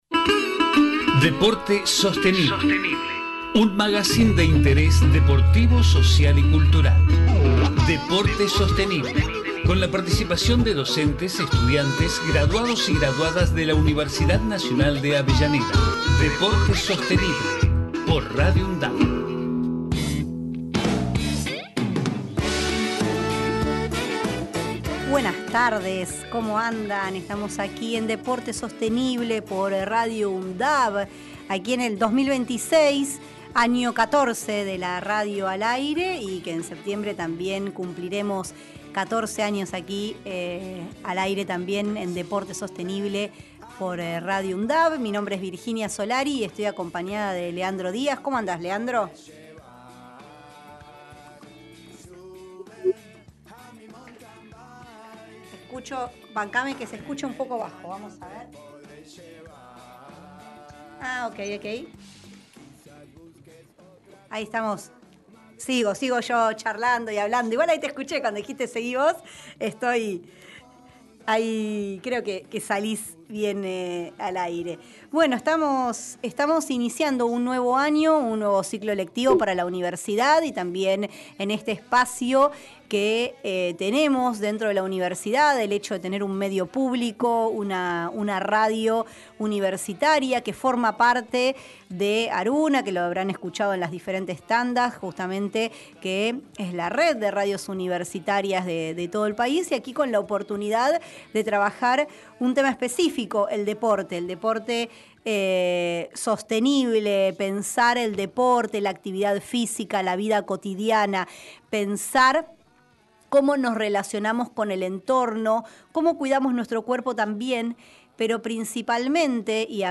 Magazine de interés deportivo, social y cultural que se emite desde septiembre de 2012.
Con la participación de docentes, estudiantes y graduados/as de la Universidad Nacional de Avellaneda.